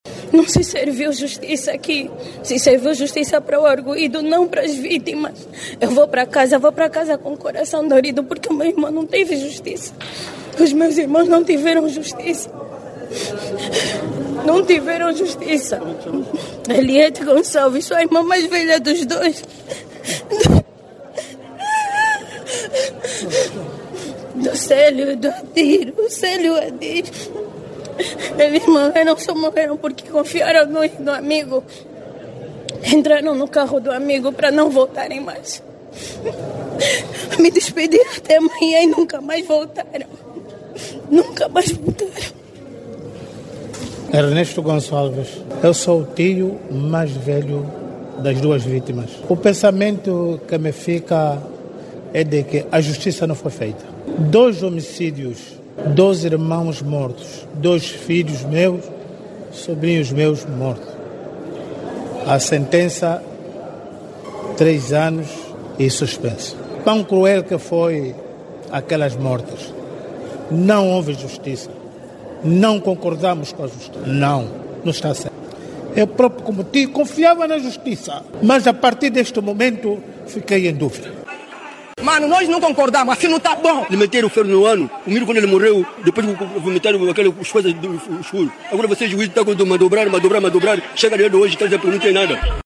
Siga o áudio do clamor das famílias que manifestaram o seu descontentamento aos julgamentos citados: